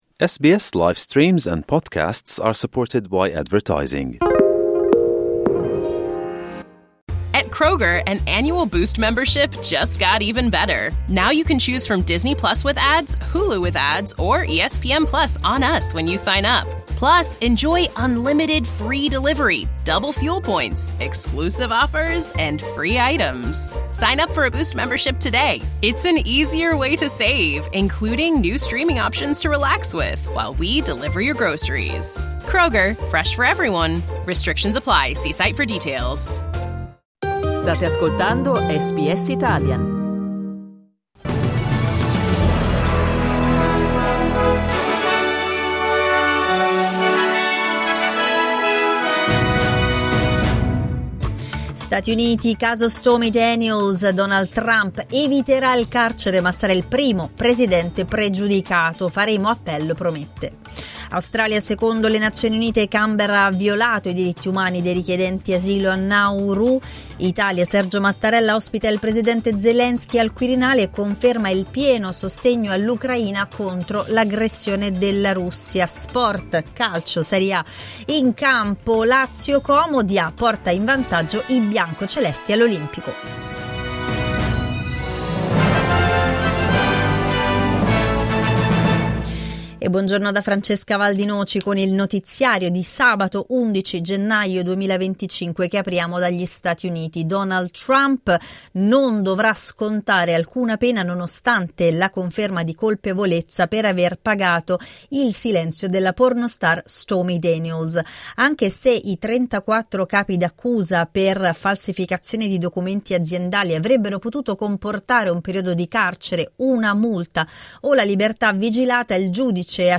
Giornale radio sabato 11 gennaio 2025
Il notiziario di SBS in italiano.